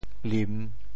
檩 部首拼音 部首 木 总笔划 17 部外笔划 13 普通话 lǐn 潮州发音 潮州 lim3 文 中文解释 檩 <名> 架在房梁上托住椽子的横木。